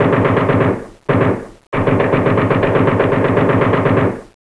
CATAPULT.WAV